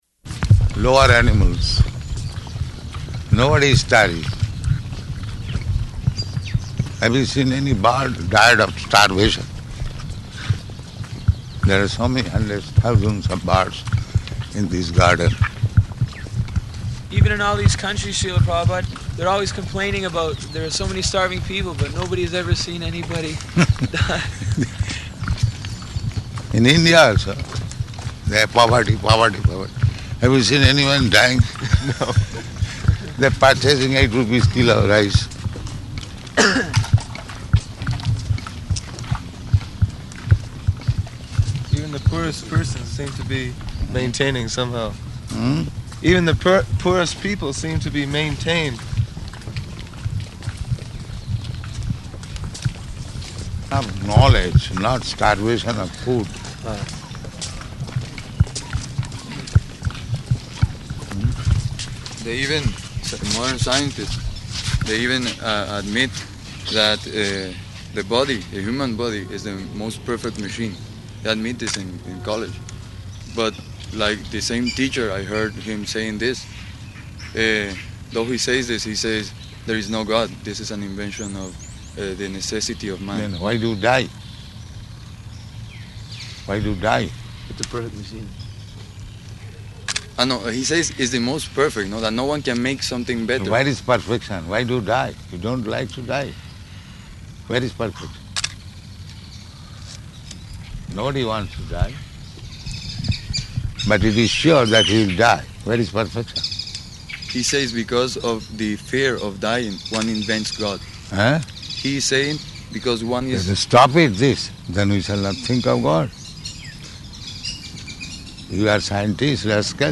Morning Walk
Type: Walk
Location: Caracas